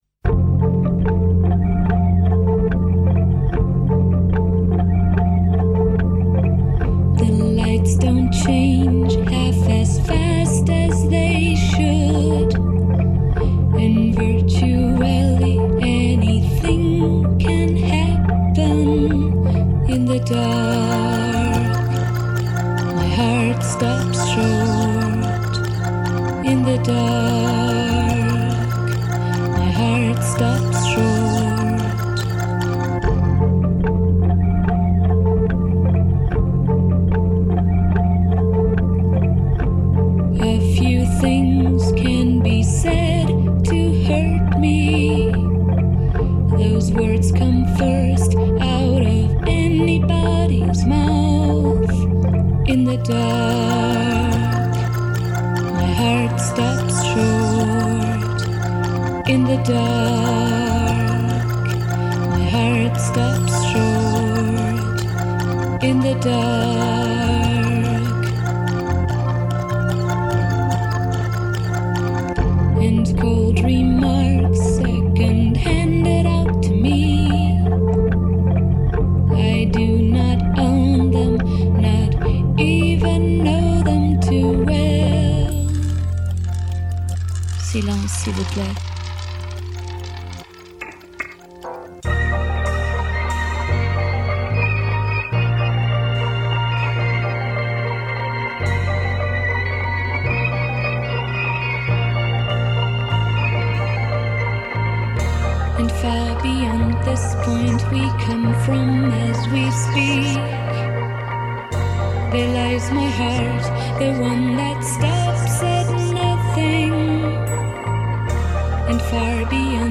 This is a bonus-track
It was recorded in June 1995 inbetween
Though this song is very gloomy and sad, again it is